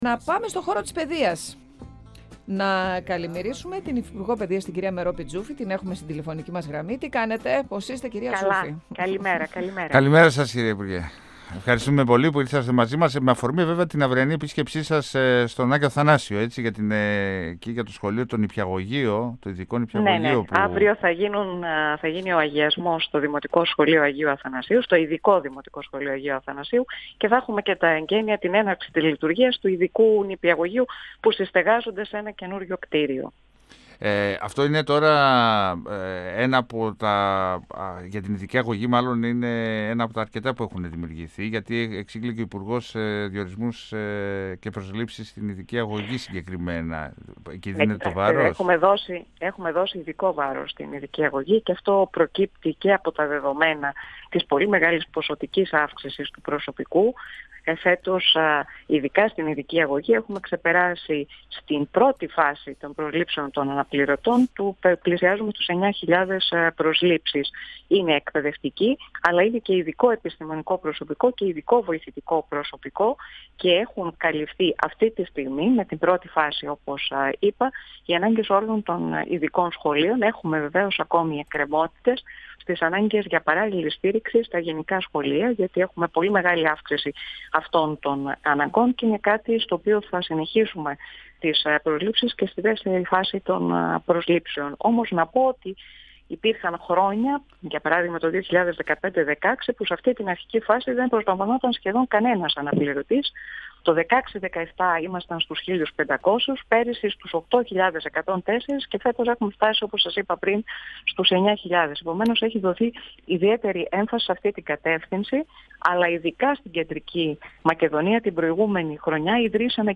Για μεμονωμένα περιστατικά αναφορικά με την τοποθέτηση εκπαιδευτικών σε παραμεθόριες περιοχές, τα οποία στην επόμενη φάση θα αντιμετωπιστούν, έκανε λόγο, μιλώντας στον 102FM του Ραδιοφωνικού Σταθμού Μακεδονίας της ΕΡΤ3, η υφυπουργός Παιδείας, Μερόπη Τζούφη. Αναφορικά με την αλλαγή του ωραρίου η υφυπουργός επεσήμανε πως δεν αποφασίζει μόνο του το υπουργείο και συνδιαλέγεται για το θέμα με τους συνδικαλιστικούς φορείς, καθώς φαίνεται υλοποιήσιμο στις μεγαλύτερες ηλικίες.
Αύριο παρουσία της υφυπουργού θα γίνουν τα εγκαίνια του ειδικού δημοτικού σχολείου Αγίου Αθανασίου, με την ταυτόχρονη λειτουργία του ειδικού νηπιαγωγείου. 102FM Συνεντεύξεις ΕΡΤ3